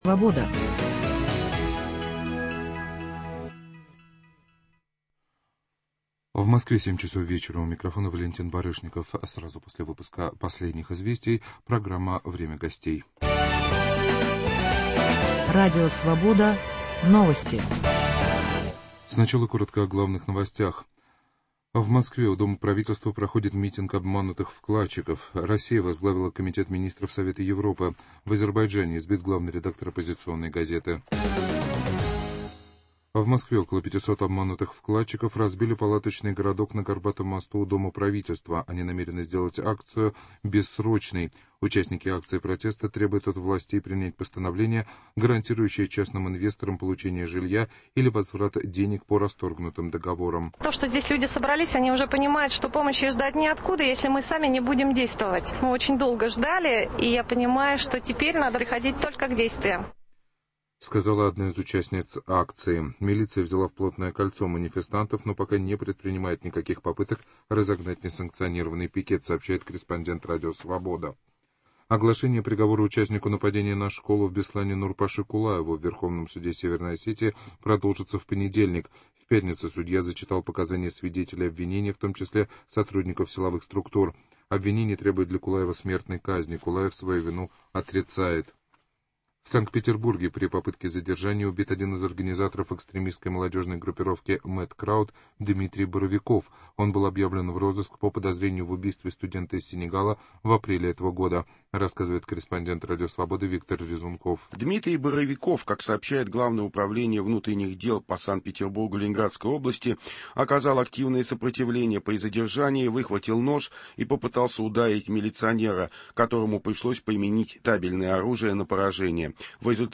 Есть ли в России сегодня моральные авторитеты. Об этом в программе «Время гостей» 19 мая, в 19 часов после новостей говорим с историком Леонидом Баткиным и политологом Андреем Пионтковским.